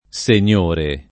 SenL1re o SenL0re] s. m. e agg. m. e f. — possibili una pn. chiusa dell’-o- (sull’analogia di maggiore, minore, ecc.) e una pn. aperta (regolare per principio in voci dòtte): preval. l’aperta a Fir. (come anche per l’opposto iuniore), divise in due la rimanente Tosc. e l’It. mediana (a differenza di iuniore, per cui di nuovo prevale l’aperta)